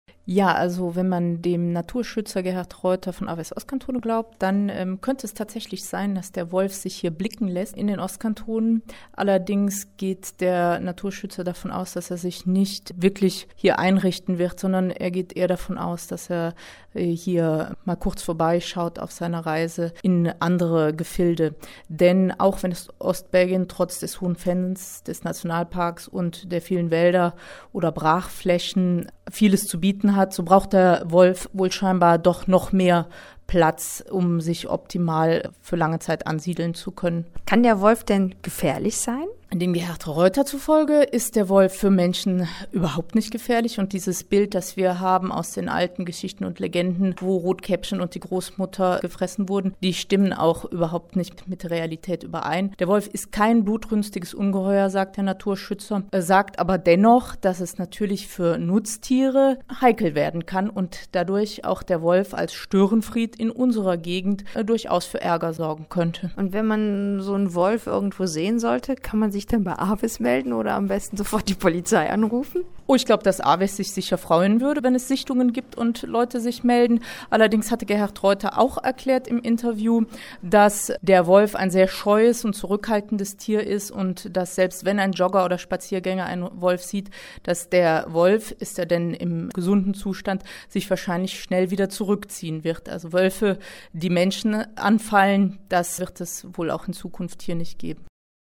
unterhalten